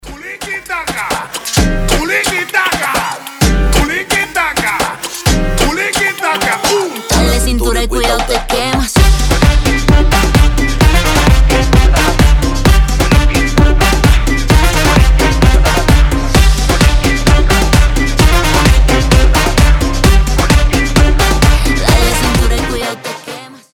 • Качество: 320, Stereo
ритмичные
зажигательные
веселые
заводные
Moombahton
испанские
Весёлая испанская музычка